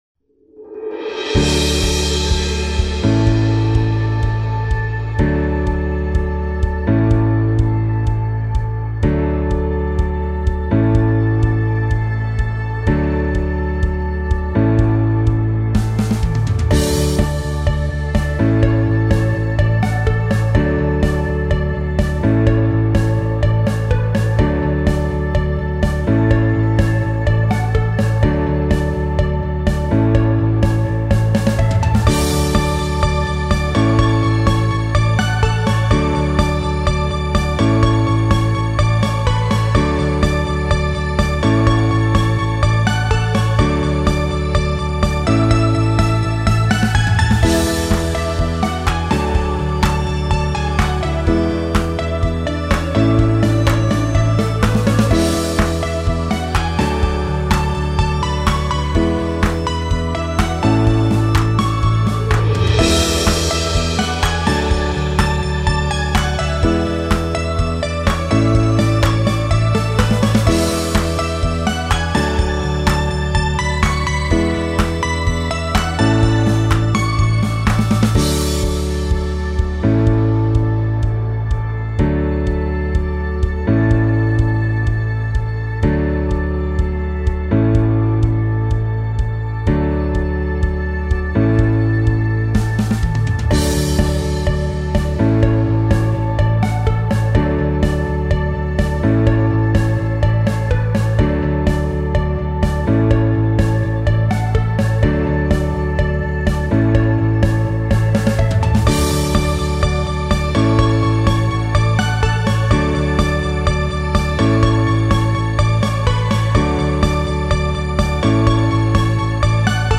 最初は落ち着いたピアノから始まり、曲が進むにつれて、シンセリードが入ってきて明るい雰囲気を出していきます。